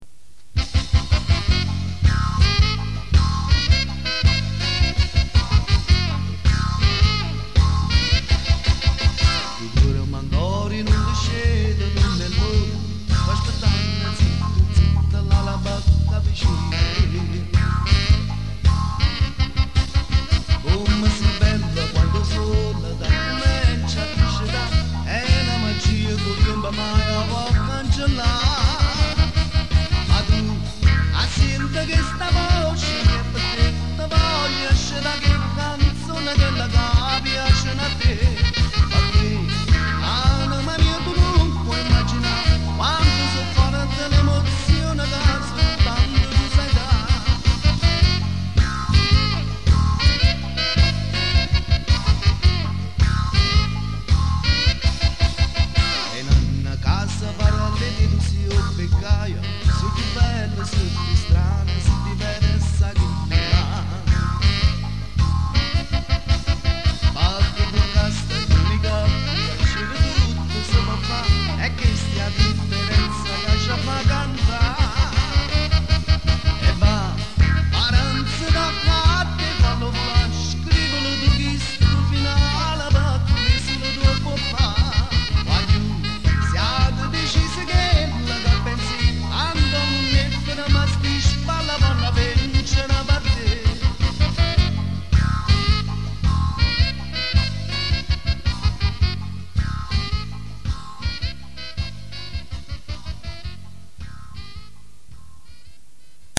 versione live